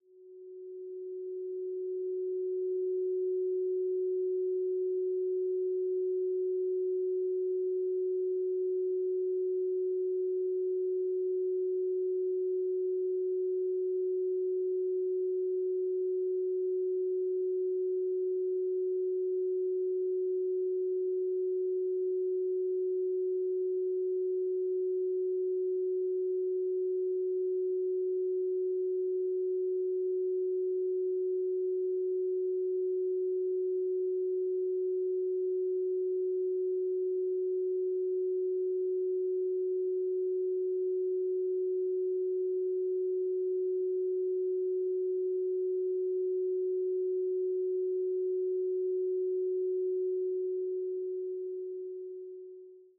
Duration: 0:53 · Genre: Romantic · 128kbps MP3